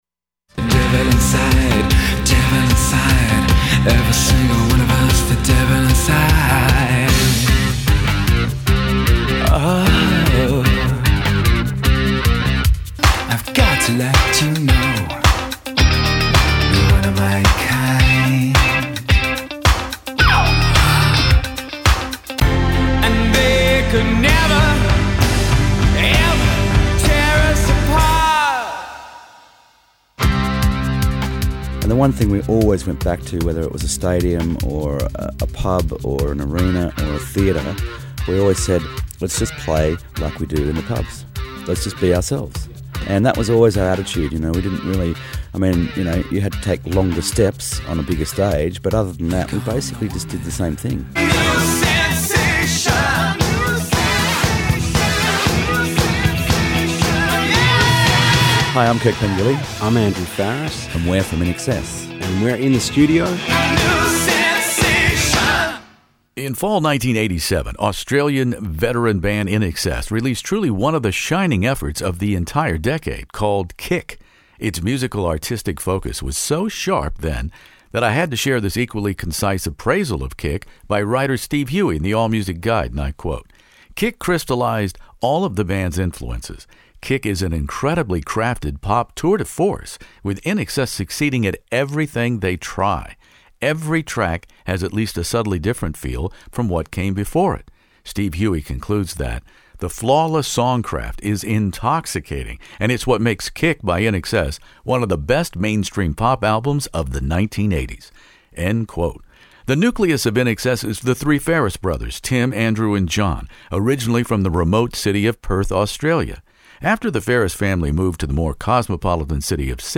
One of the world's largest classic rock interview archives, from ACDC to ZZ Top, by award-winning radio personality Redbeard.
Keyboard player/songwriter Andrew Farriss is joined by multi-instrumentalist Kirk Pengilly and guitarist Tim Farriss here In the Studio to share the backstory behind “New Sensation”, “Devil Inside”,”Never Tear Us Apart”,”Need You Tonight”, and the stunning “Kick” title song. Also we share my interviews with the mercurial late INXS singer/lyricist Michael Hutchence on the international blockbuster Kick.